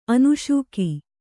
♪ anuśuki